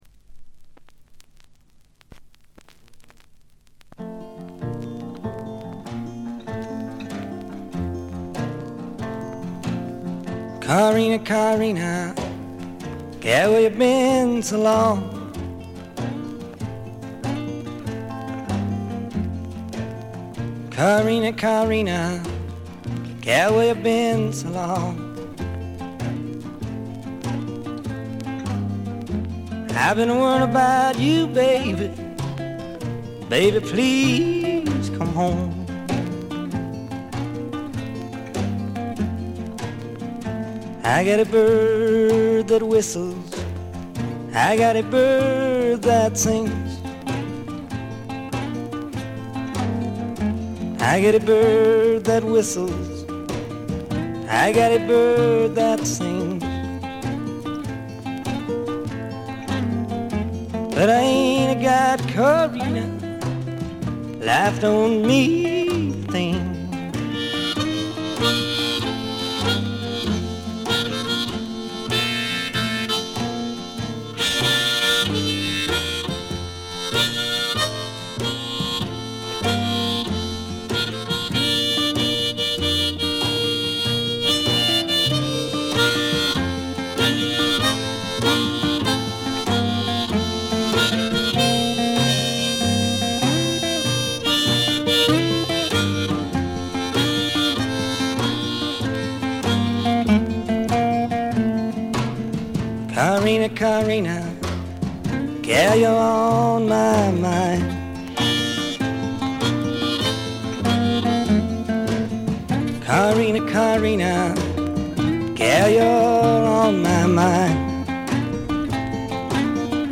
全体にバックグラウンドノイズ、チリプチ多め大きめですが音は見た目よりずっといい感じです。
試聴曲は現品からの取り込み音源です。